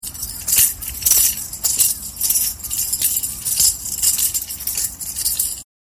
これがフットタンバリンだ。
ｼﾞｬｯｼﾞｬｼﾞｬｯｼﾞｬｼﾞｬｯｼﾞｬｼﾞｬｯｼﾞｬｼﾞｬｯｼﾞｬｼﾞｬｯｼﾞｬｼﾞｬｯｼﾞｬｼﾞｬｯｼﾞｬｼﾞｬｯｼﾞｬ